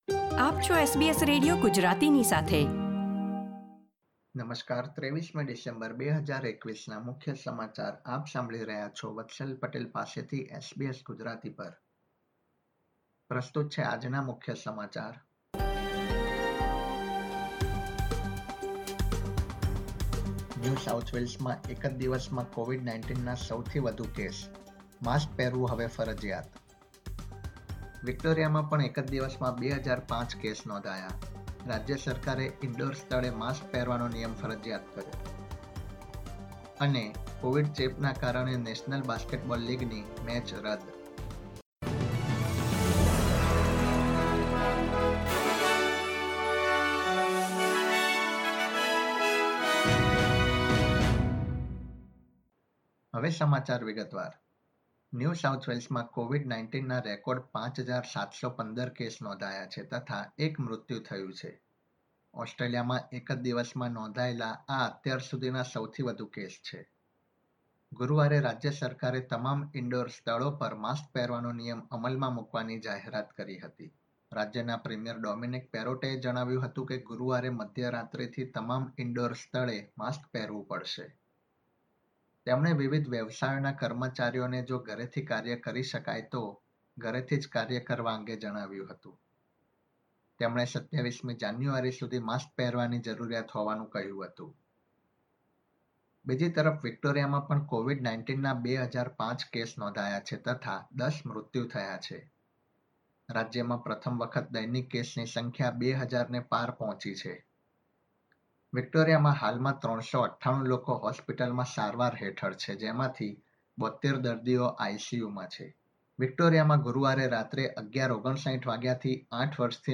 SBS Gujarati News Bulletin 23 December 2021